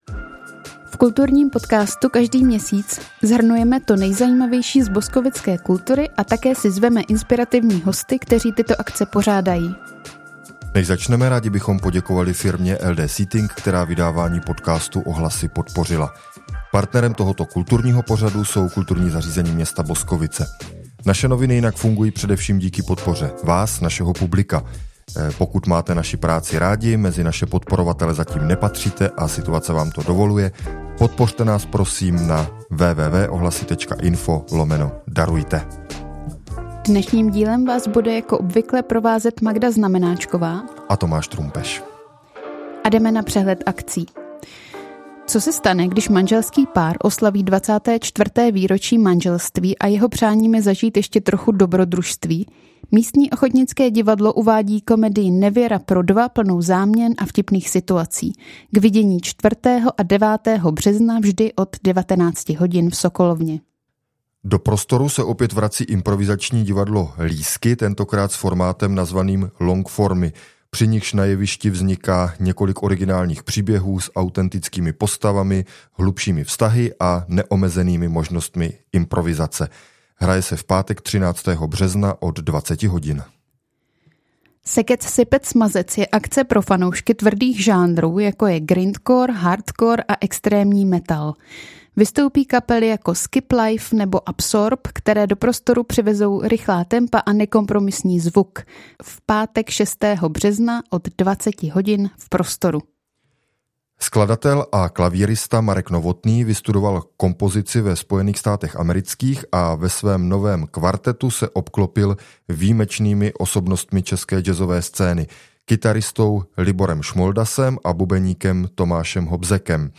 Kulturní výběr na březen + rozhovor o příští podobě a využití parku u skleníku